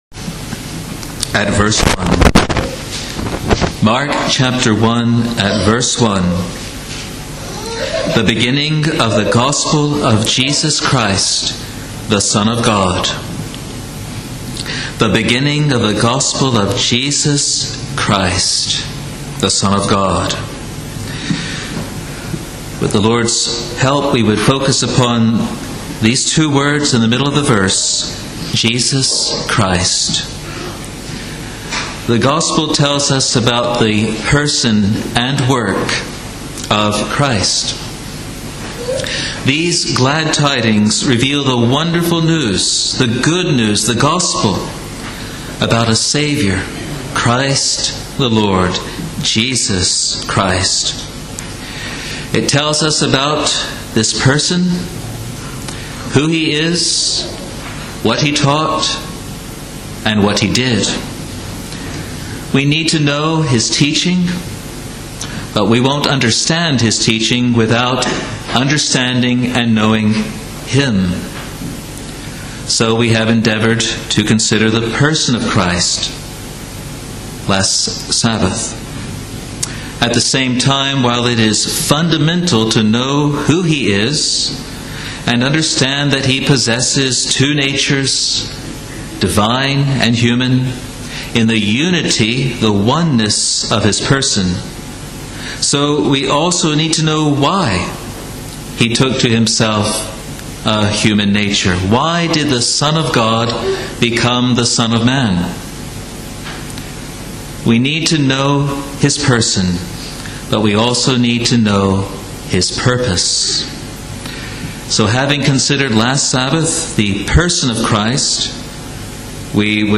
Sermons | Free Presbyterian Church of Scotland in New Zealand